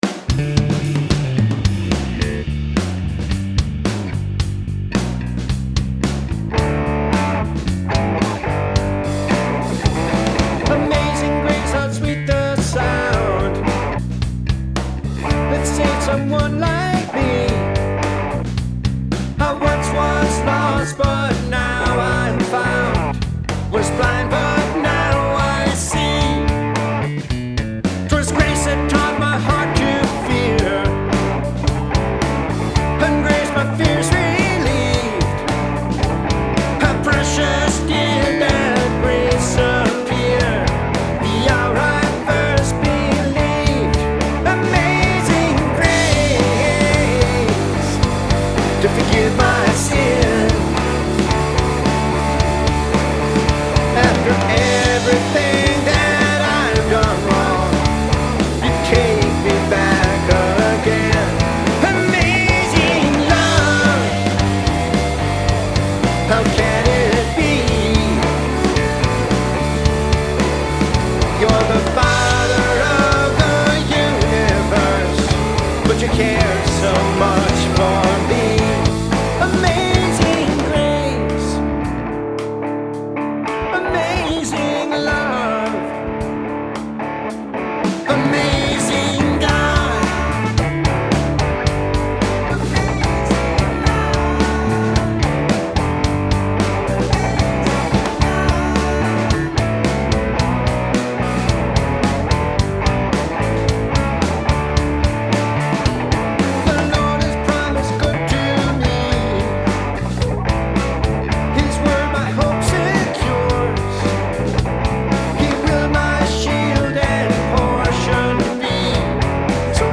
Good ole' nostalgic CCM. Some of the guitar parts were unsalvageable so I dumped them. The whole track is mixed with 3 plugins.
I stuck an auotuner on the vocal and a David Eden amp simulator on the bass. ...the bass isn't translating real well on small speakers.